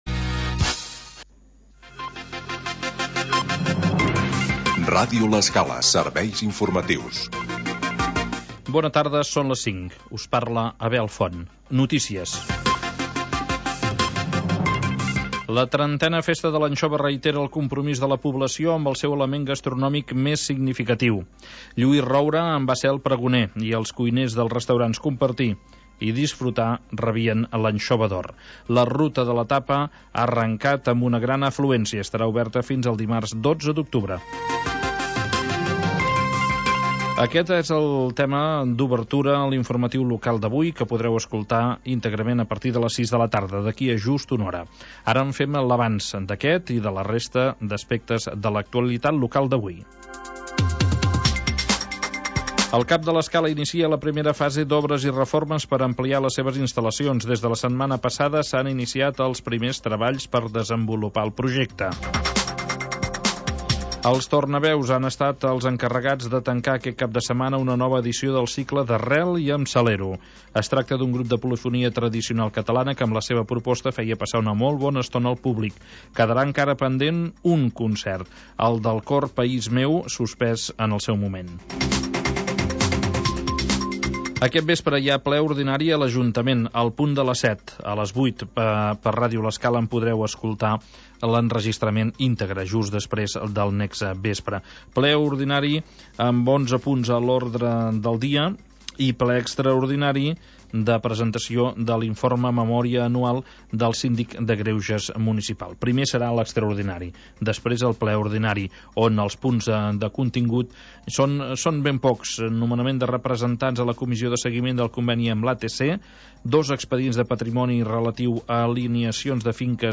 Magazín territorial que parla del mar